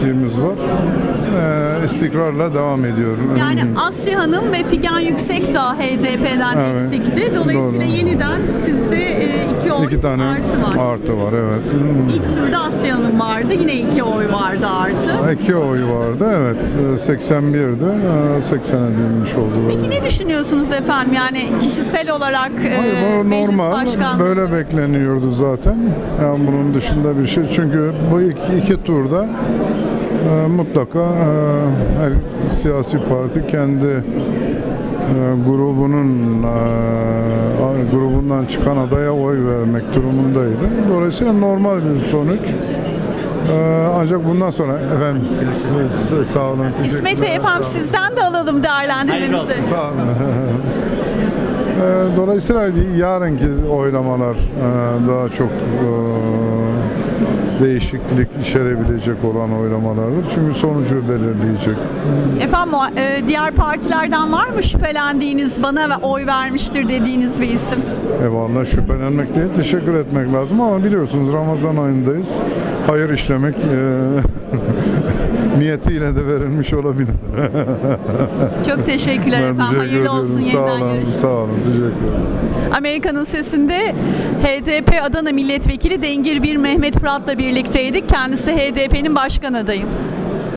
Dengir Mir Mehmet Fırat'la söyleşi